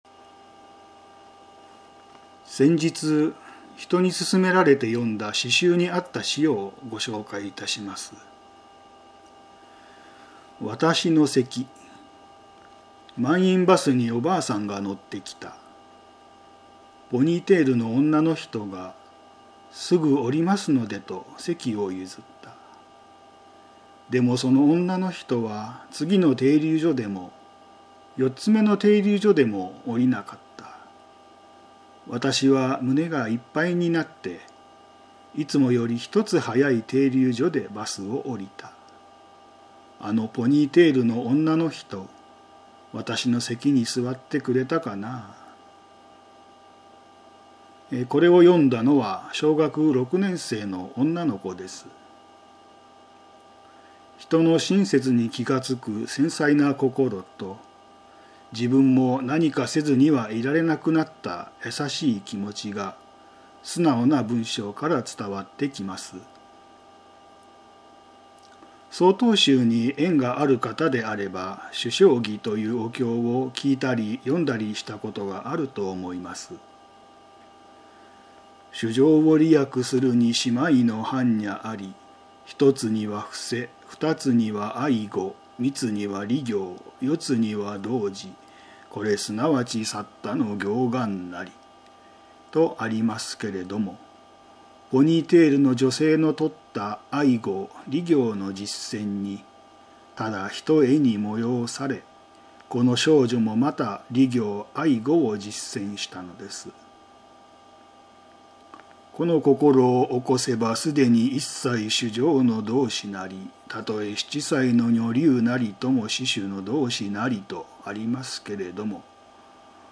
曹洞宗岐阜県宗務所 > テレフォン法話 > 「愛語よく廻天の力あり」